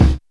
kits/RZA/Kicks/WTC_kYk (71).wav at main